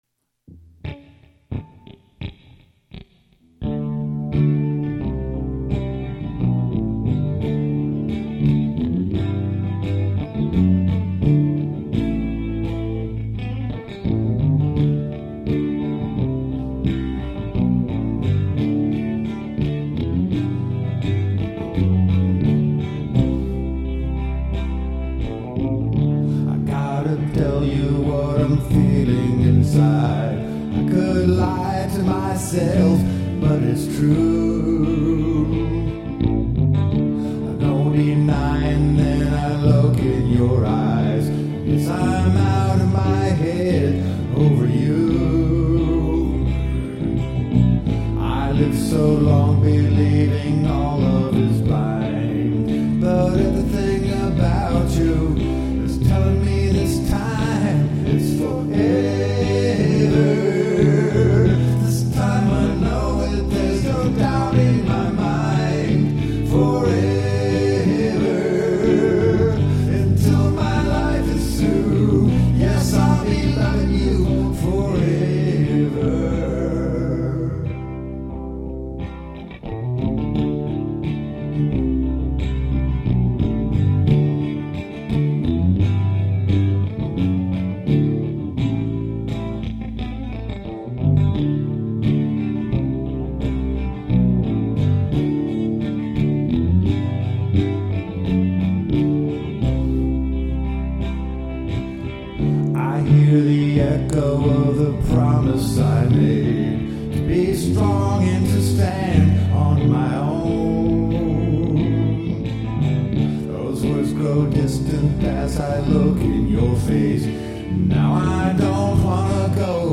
(longer - still needs solo) MP3 »